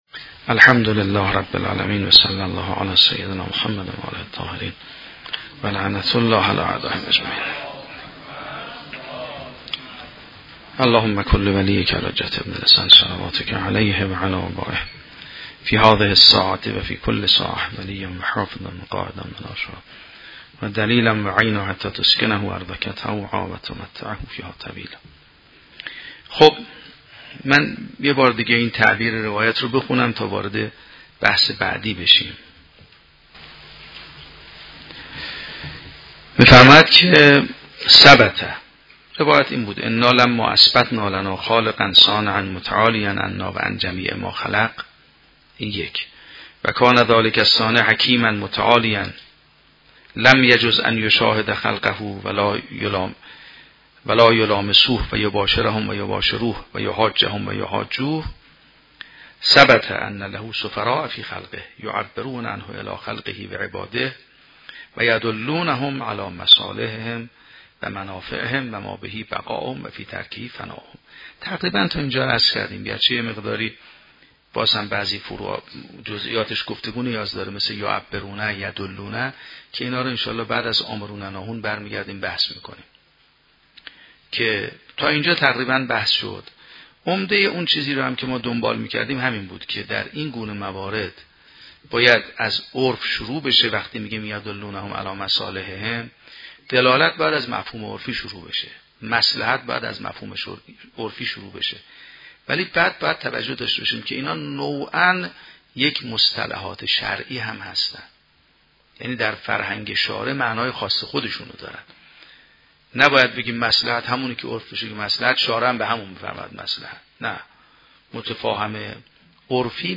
شرح و بررسی کتاب الحجه کافی توسط آیت الله سید محمدمهدی میرباقری به همراه متن سخنرانی ؛ این بخش : شروع بحث امر و نهی اولیاء الهی و تبیین نکات کلیدی بحث در روایت